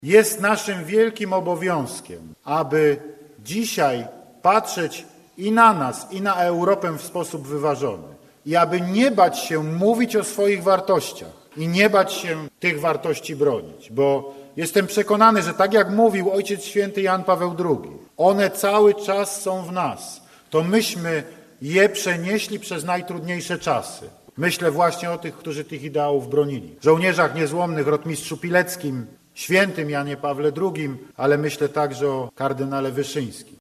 fh3o7z9ykgie27m_oredzie-prezydenta-andrzeja-dudy.mp3